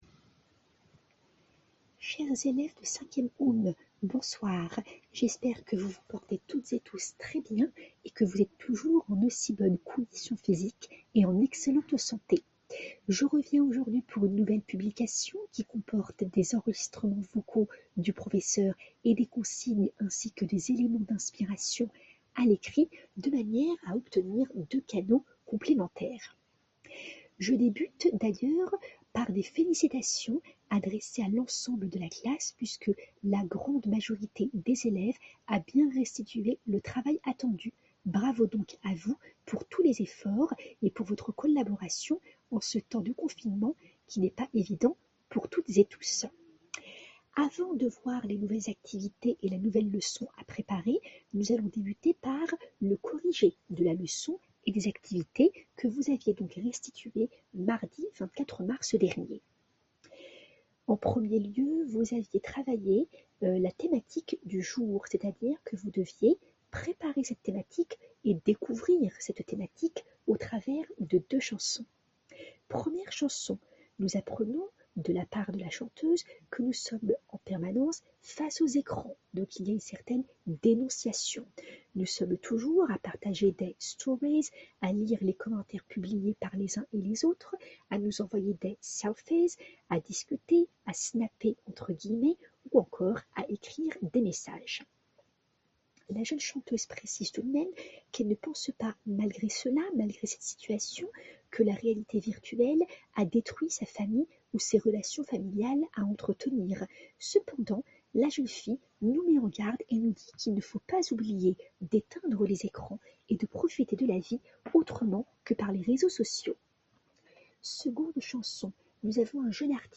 Veuillez trouver ci-après, s'il vous plaît, et sous forme d'enregistrements vocaux, le corrigé des activités faisant partie de la construction de la leçon précédente:
Audio 1 du professeur d'une durée de 08:59: